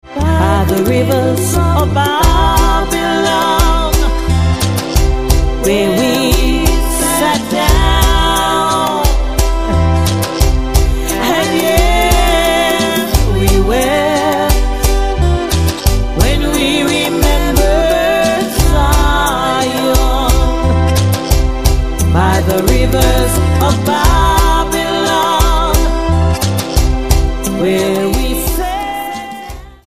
STYLE: World